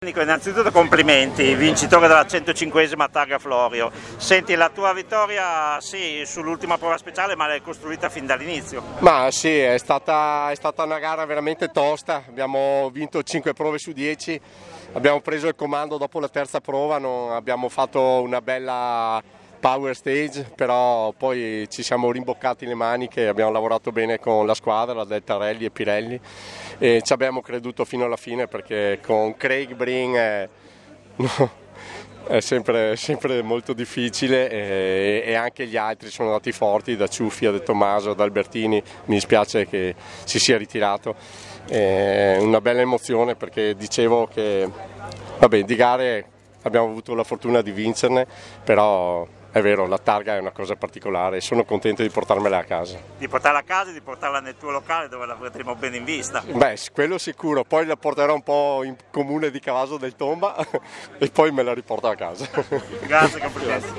Interviste di fine rally